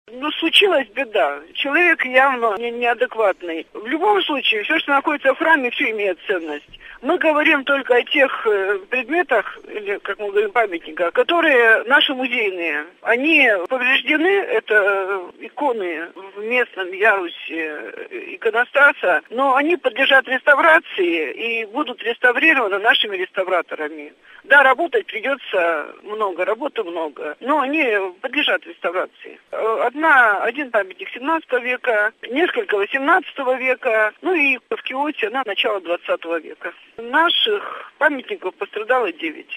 Об этом в интервью ИА «СеверИнформ